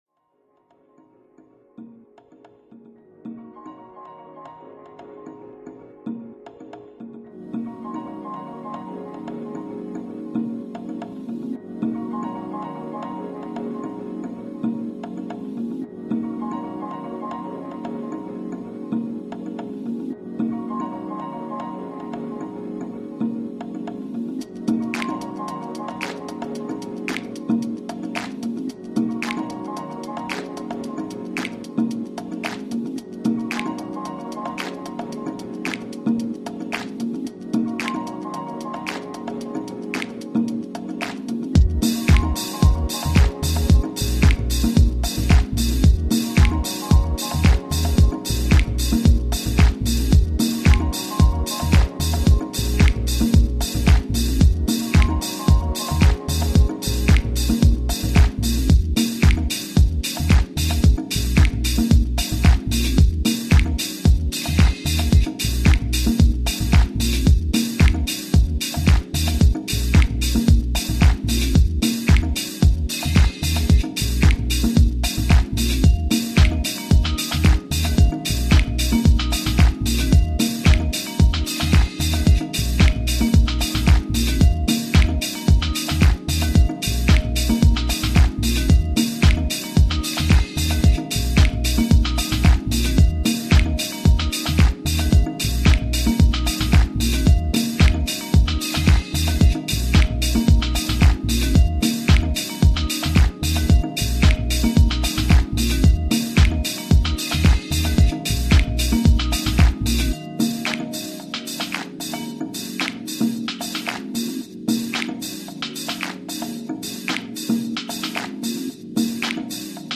warm, hook heavy deepness
Disco House